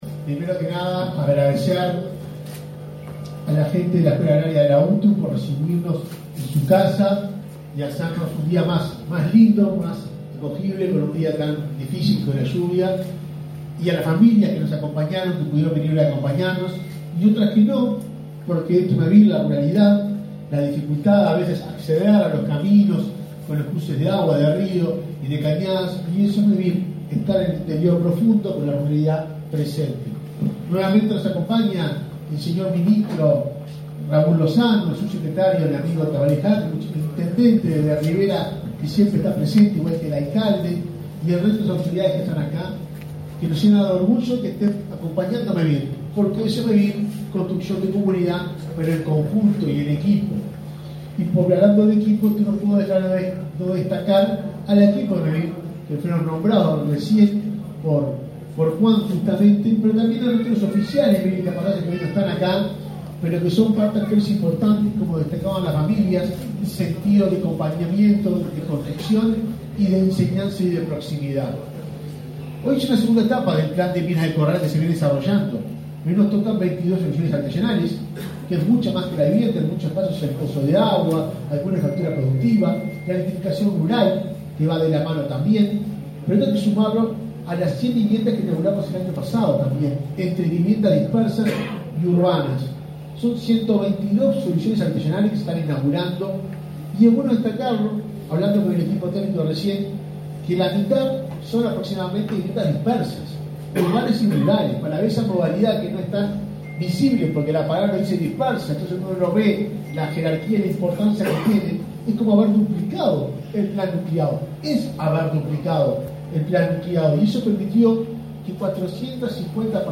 Acto por la inauguracion de viviendas en Minas de Corrales, Rivera